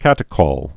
(kătĭ-kôl, -kŏl, -kōl)